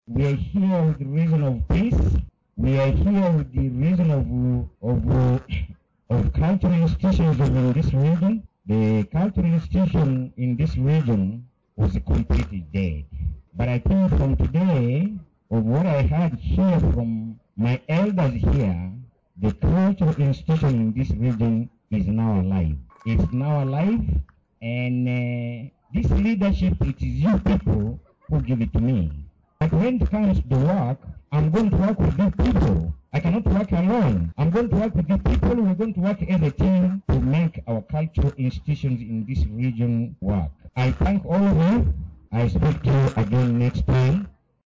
Amidst the festivities and celebrations, King Acikule Nassur himself addressed his subjects. In a humble and earnest appeal, he urged his subjects to stand beside him and work collaboratively to fortify the kingdom's prosperity.